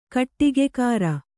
♪ kaṭṭigekāra